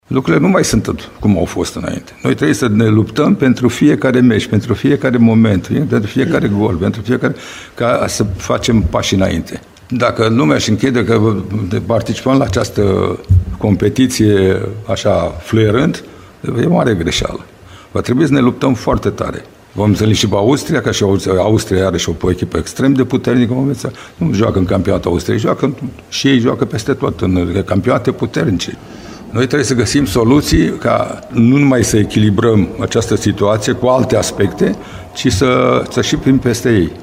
Selecționerul Mircea Lucescu a vorbit în pre-ziua întâlnirii despre actualul context al preliminariilor:
21-mar-8.30-Lucescu-despre-preliminarii.mp3